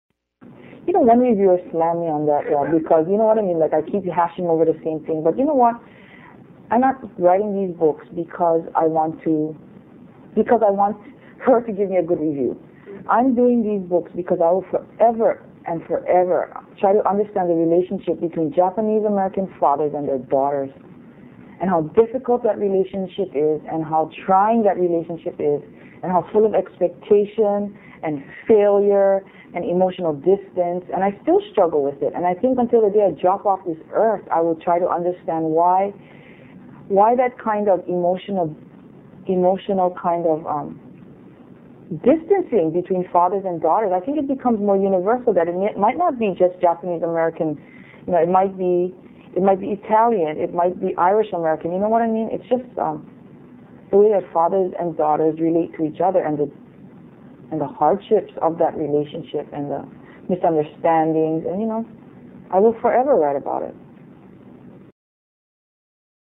Q&A with Lois-Ann Yamanaka